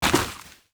Light  Dirt footsteps 5.wav